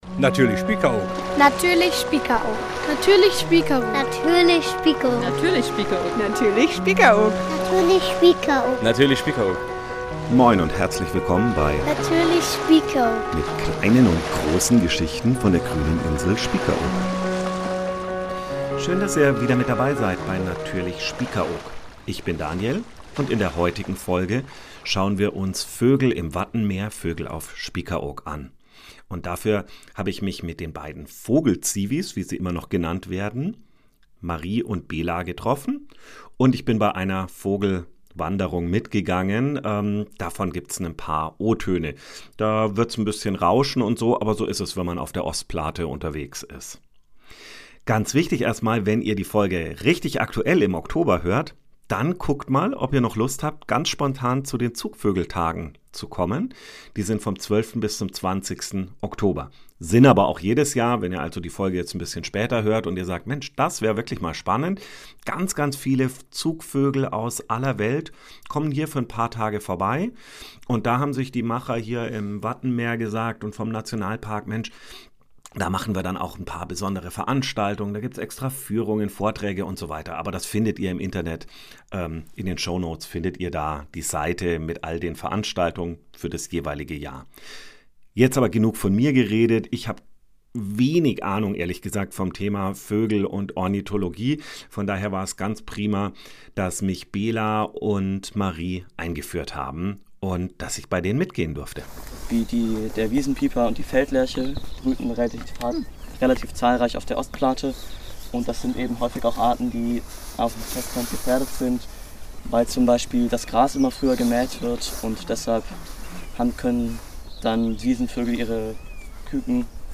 Das Wattenmeer als „Tankstelle“ für den weltweitenVogelzug wird vorgestellt. Wir sind live bei einer Vogelwanderung dabei.